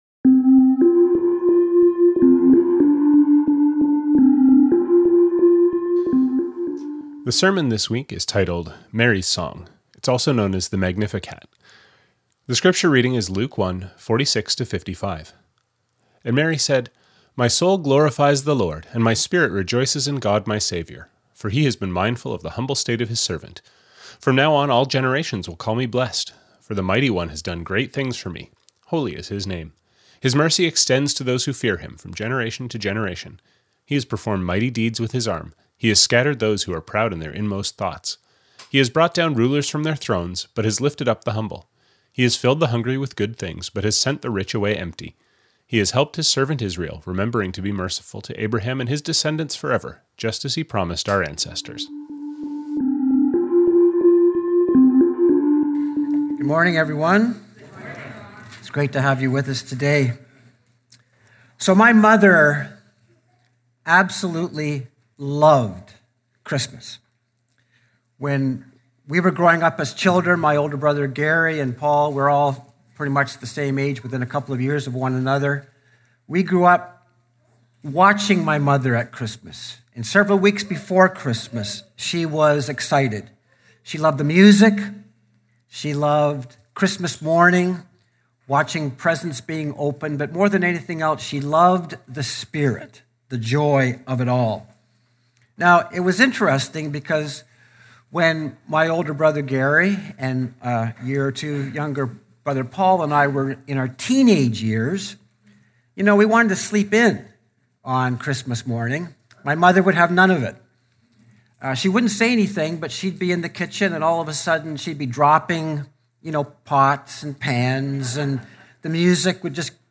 Verses from this week's sermon: Luke 1:26-27; 1:27-29; 1:29-31; 1:34-35; 1:36-37; 1:46-48; 1:48-55; James 1:1-12.